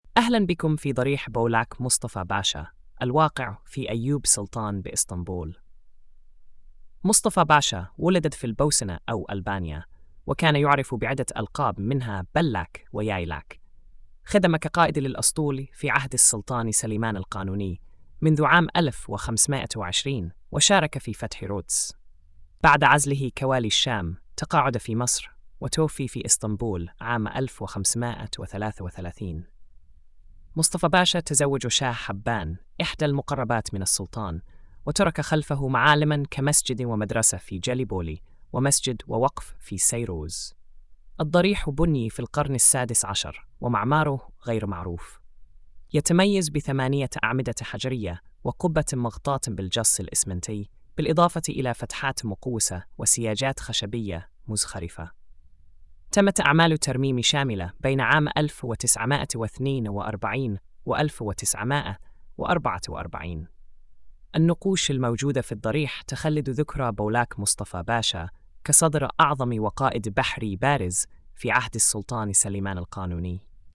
السرد الصوتي: